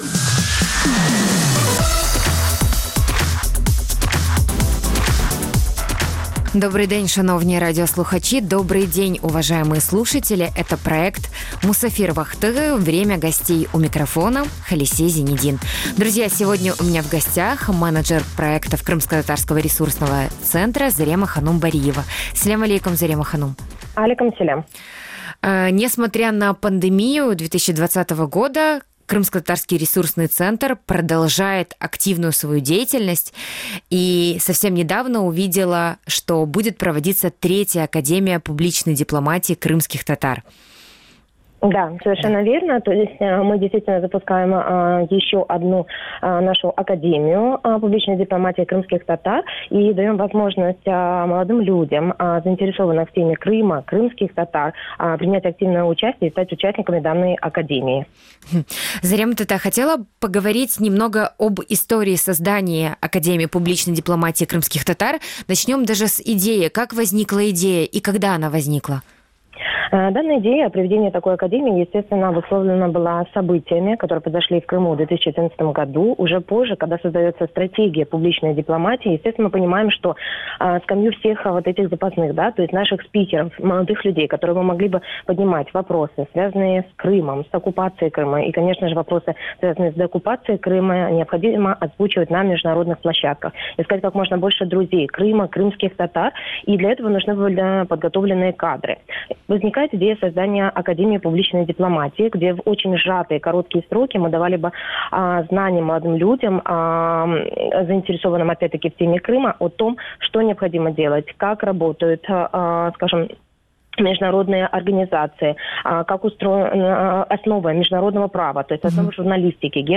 В гостях эфира